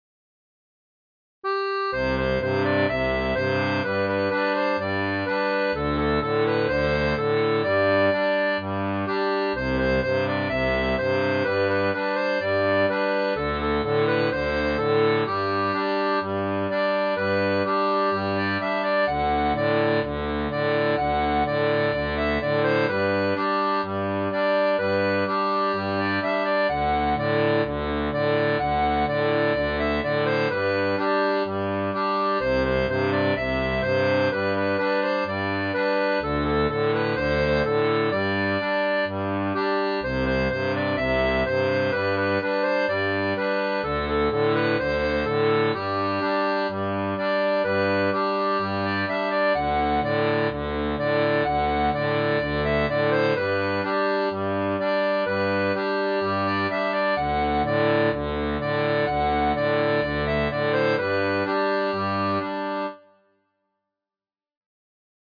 Folk et Traditionnel